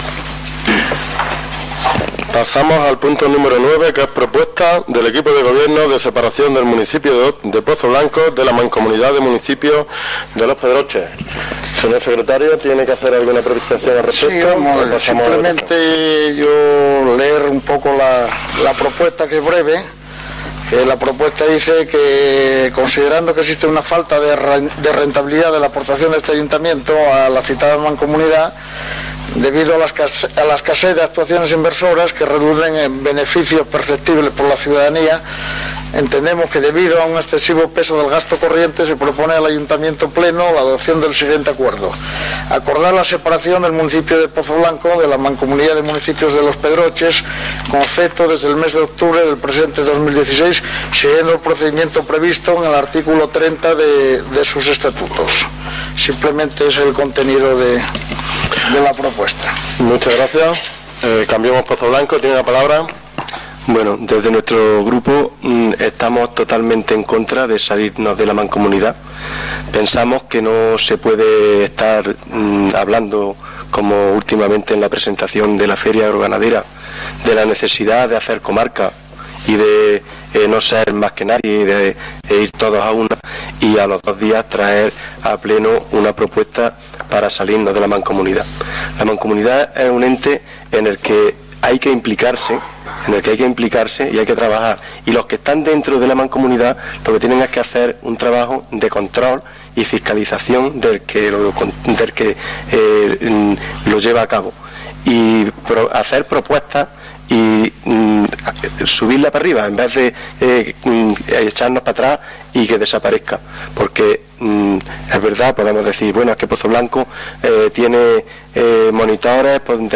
Escuchamos aquí todas las intervenciones y votaciones (duración: 45 minutos).